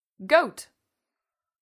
goat.mp3